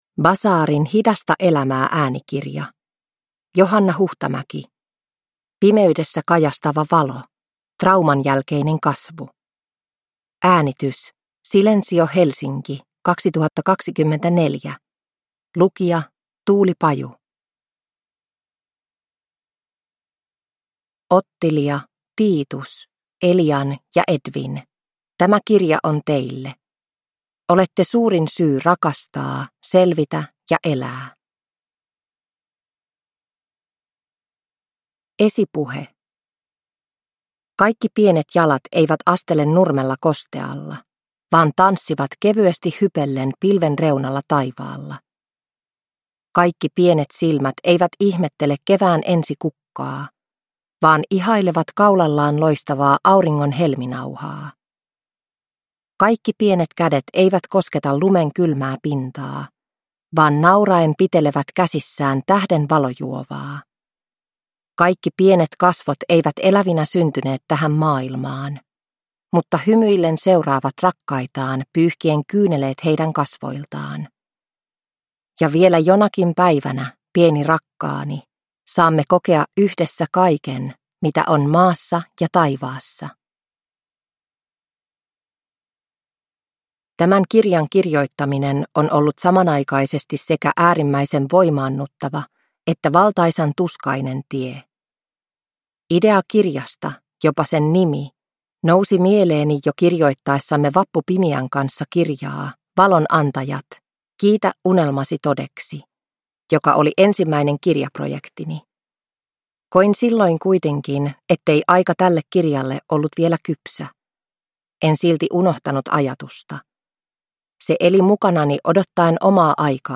Pimeydessä kajastava valo – Ljudbok